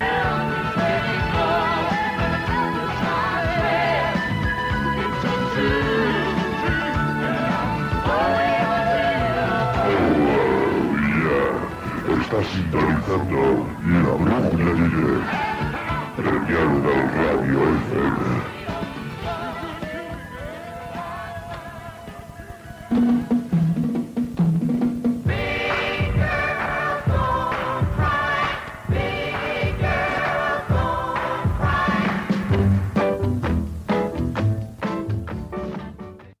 c40375e4773c0ec1c272fcecea79ed14144c3107.mp3 Títol Premià de Dalt Ràdio Emissora Premià de Dalt Ràdio Titularitat Pública municipal Descripció Identificació de l'emissora i tema musical.